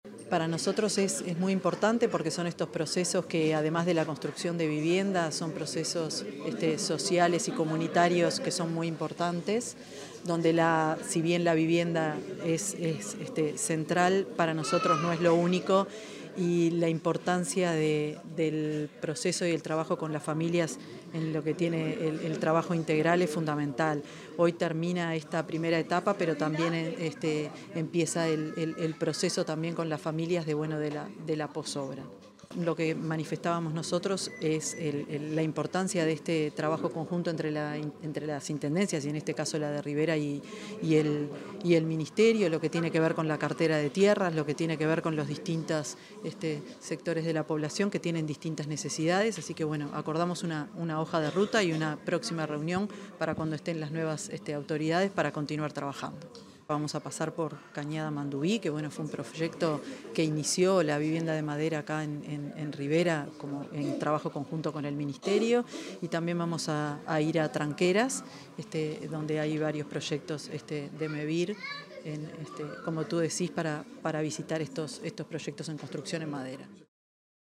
Palabras de la ministra de Vivienda, Tamara Paseyro